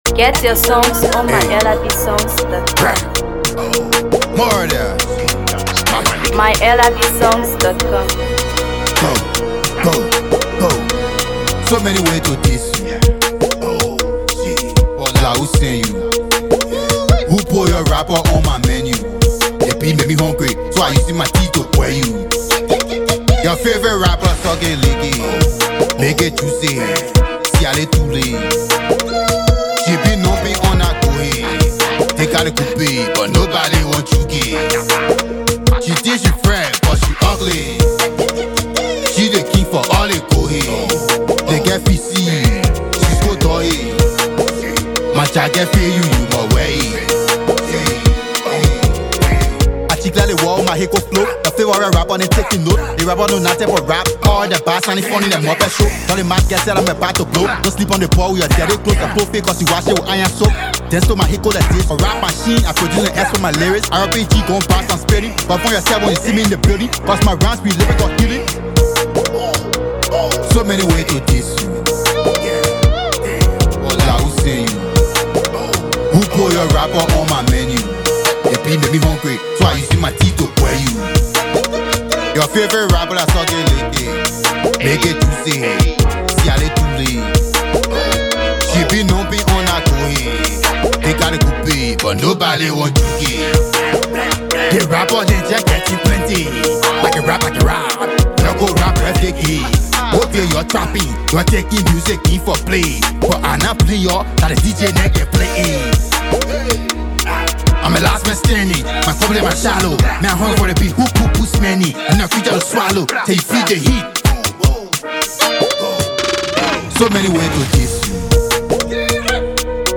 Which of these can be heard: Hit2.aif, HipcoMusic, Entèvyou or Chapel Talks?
HipcoMusic